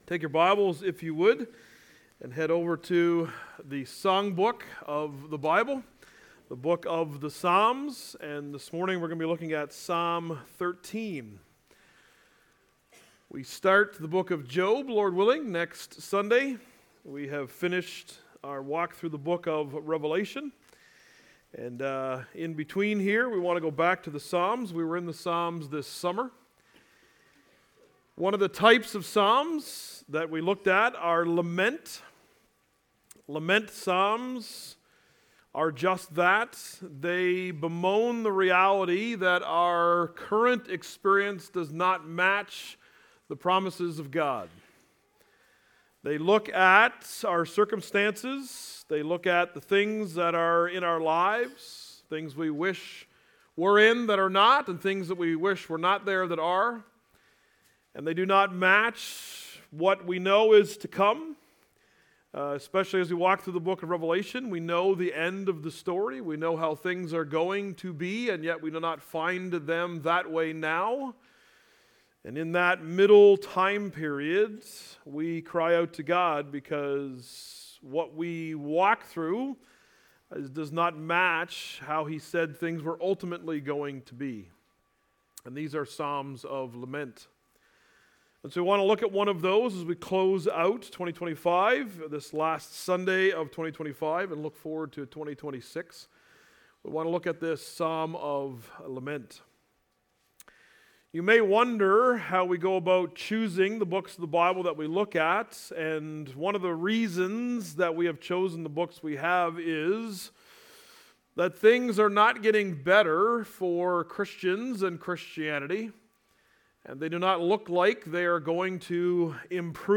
The preaching ministry of Grace Baptist Church in Charlottetown Prince Edward Island Canada.